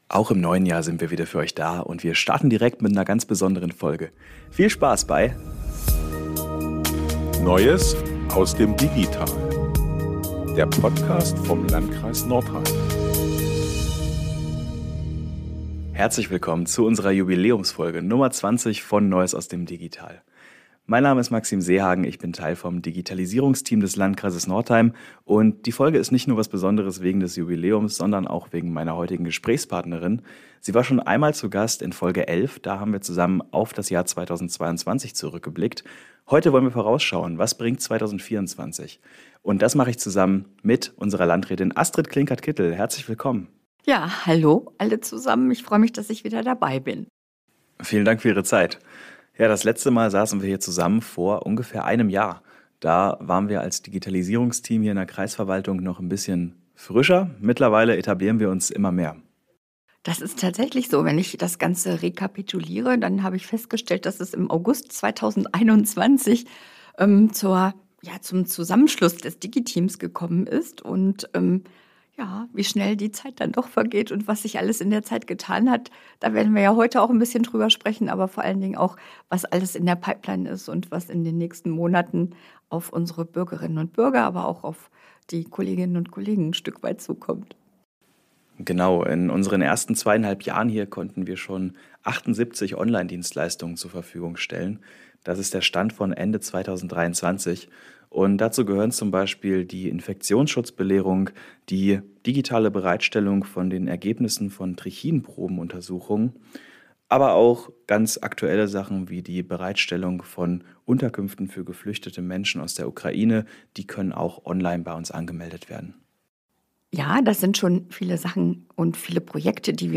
Darüber sprechen wir mit Landrätin Astrid Klinkert-Kittel in Folge 20. Es geht unter anderem um die digitale Baugenehmigung und weitere neue Online-Dienstleistungen aus den Bereichen Soziales, Verkehr und Ordnung. Außerdem zeigen wir Wege der digitalen Partizipation auf und informieren über das neue Digi-Barcamp am 25.04.2024!